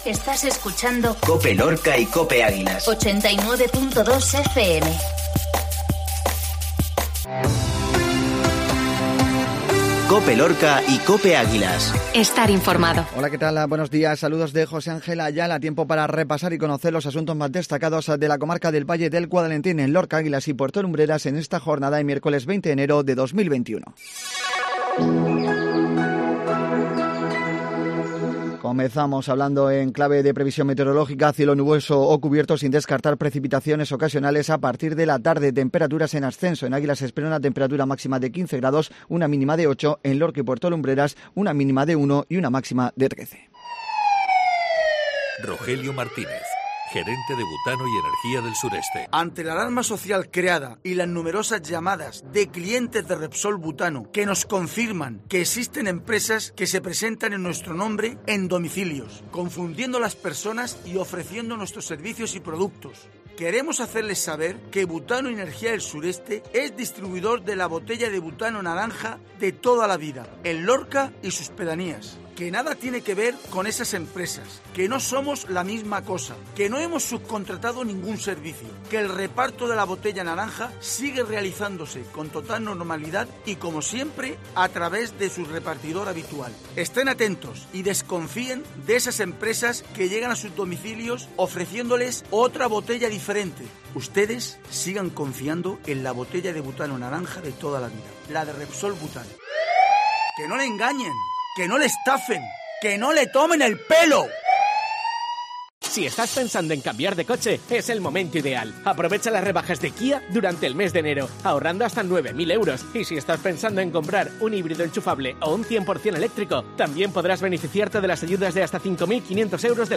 INFORMATIVO MATINAL MIÉRCOLES